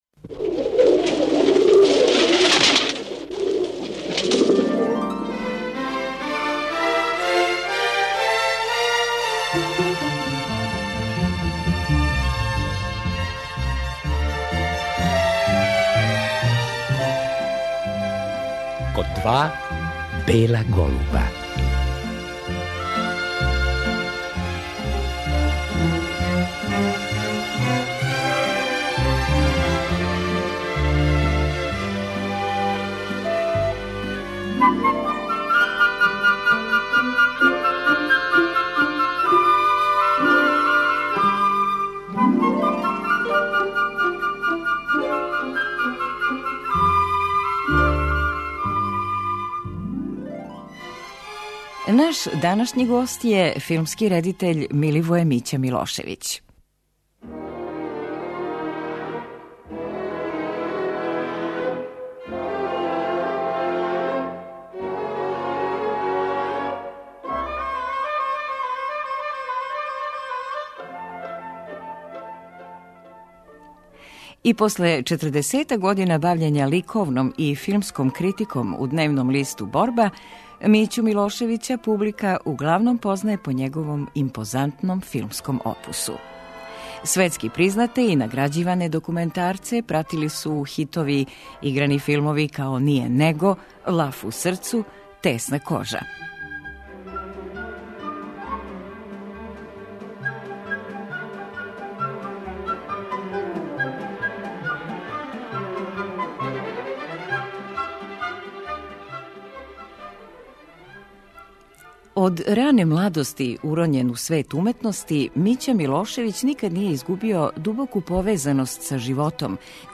Наш вечерашњи гост је филмски редитељ Миливоје Мића Милошевић. Говори нам о својој младости, студијама историје уметности и годинама испуњеним интензивним стваралачким радом у области новинарства и филма.